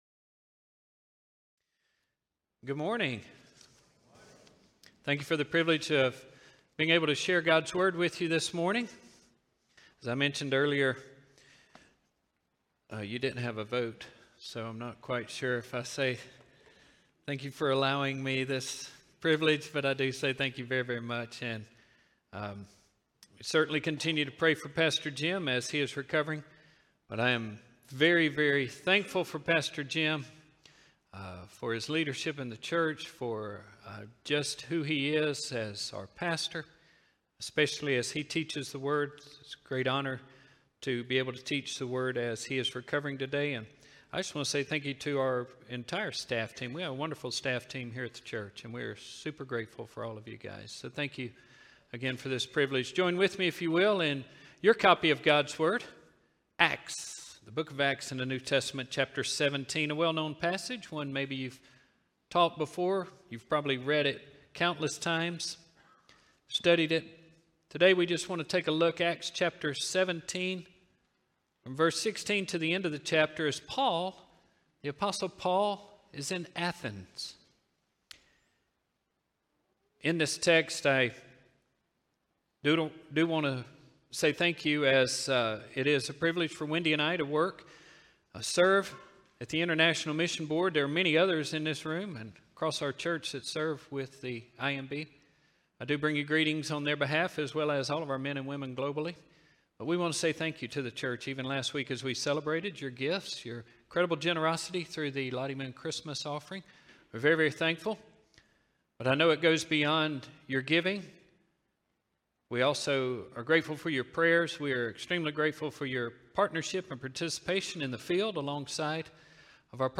Sermons | Staples Mill Road Baptist Church